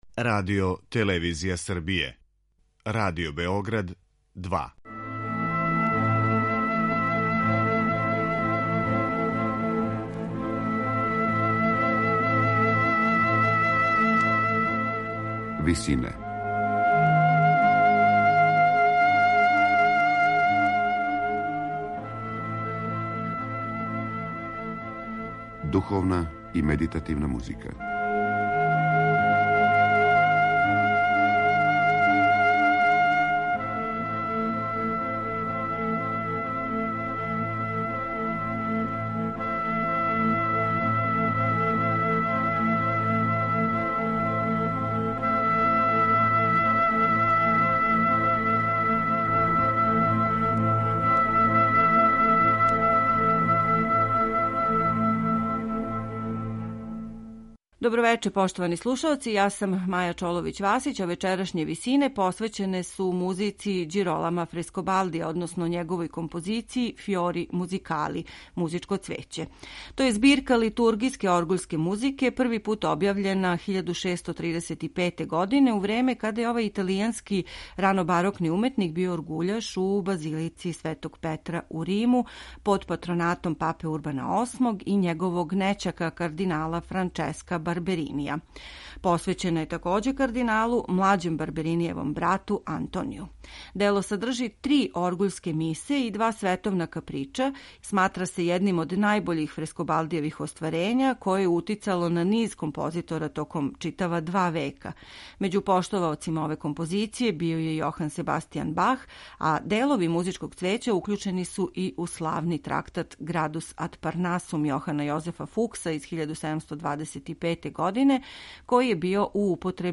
Дело које чине три оргуљске мисе овог ранобарокног италијанског композитора имало је утицаја на низ композитора током готово два века након објављивања 1635. године.